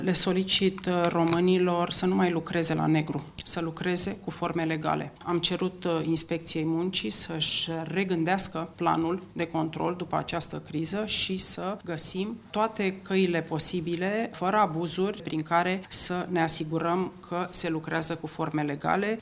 Violeta Alexandru a atras atenţia asupra fenomenului muncii la negru:
violeta-alexandru-somaj-1.mp3